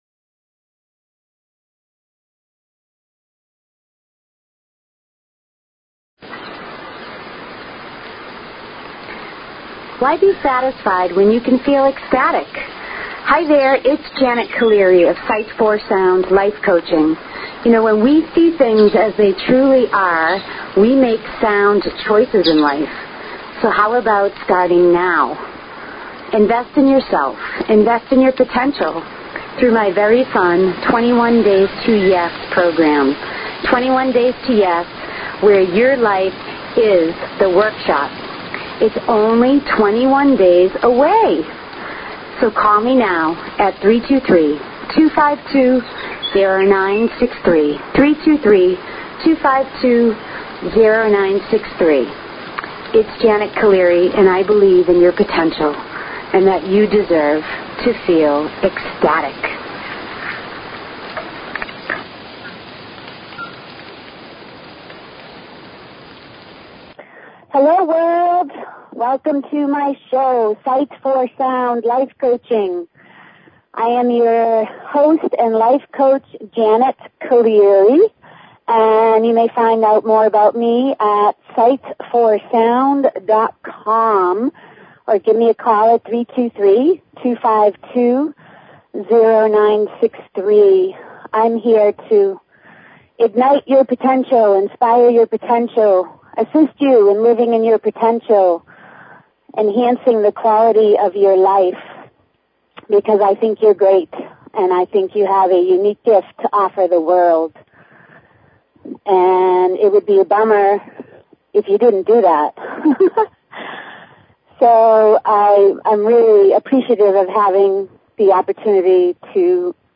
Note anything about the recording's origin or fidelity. She encourages you to call in and ask questions or share thoughts!!!